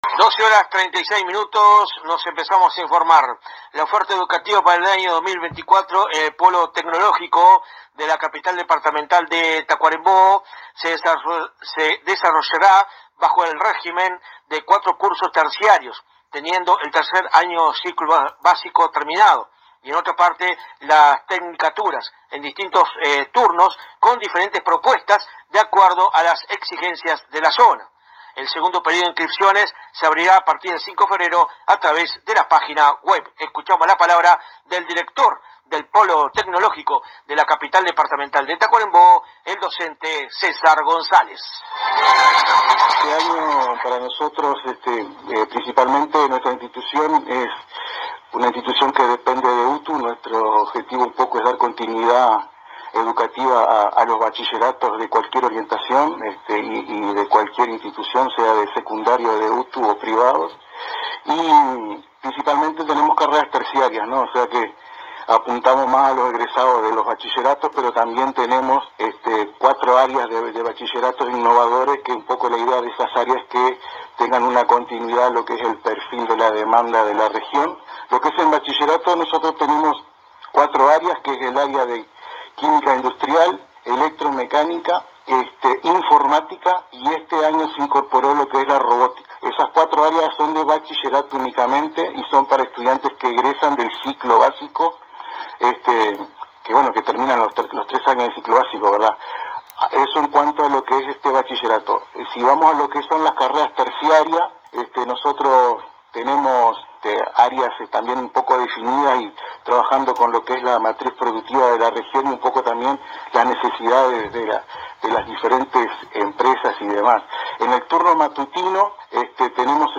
Fuente: AM 1110 Radio Paso de los Toros